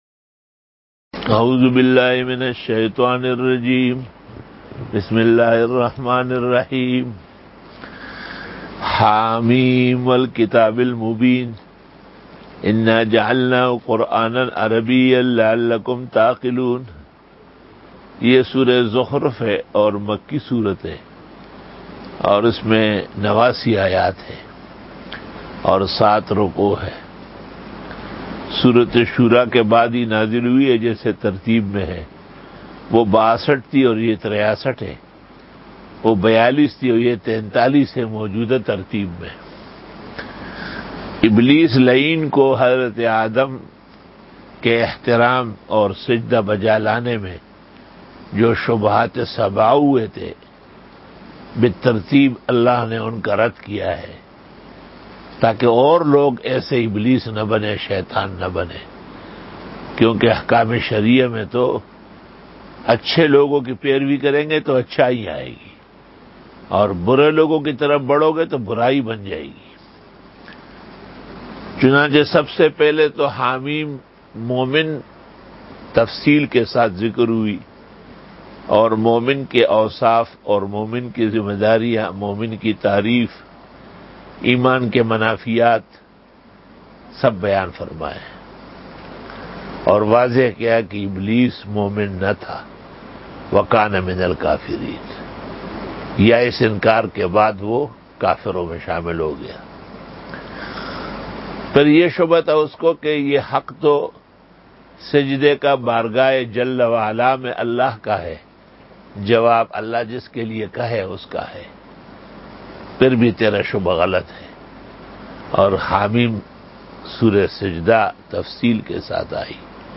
62 Quran Tafseer 14 Jun 2020 (22 Shawwal 1441 H) Sunday Day 62 .